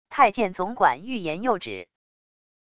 太监总管欲言又止wav音频